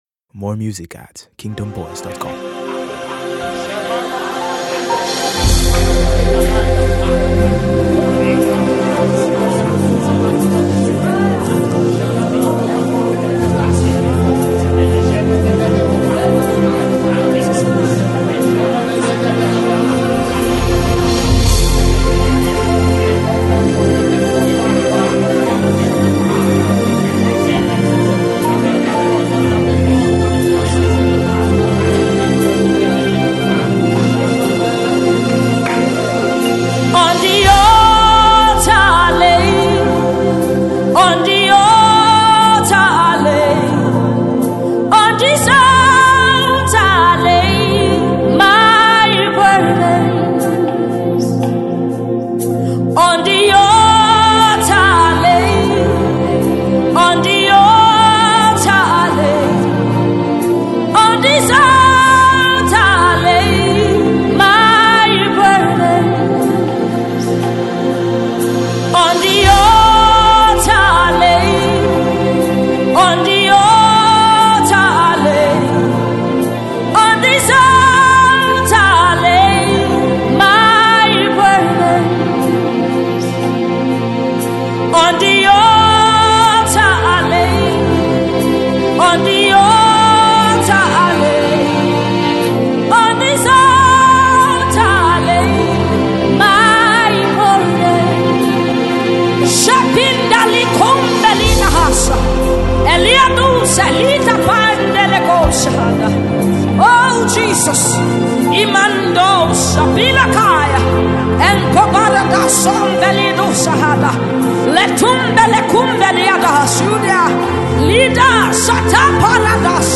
In the realm of gospel music